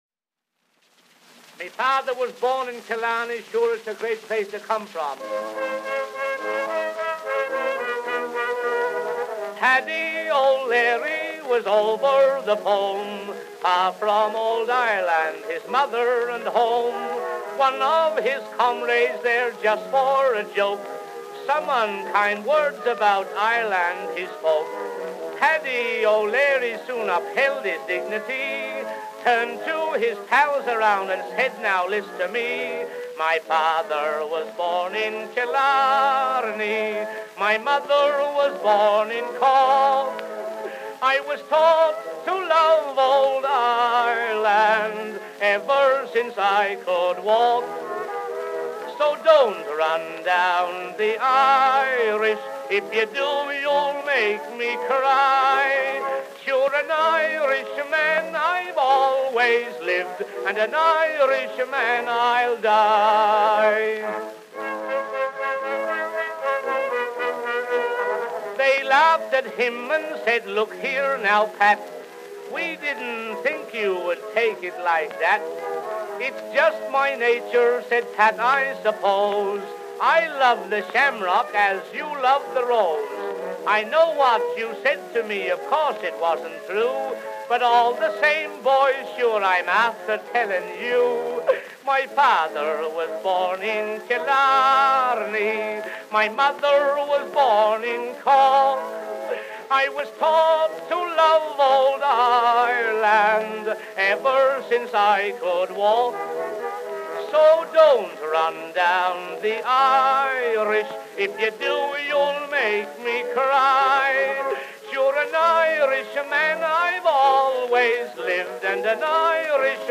for Edison by Billy Williams
Cylinder Preservation and Digitization Project, Department of Special Collections, Donald C. Davidson Library,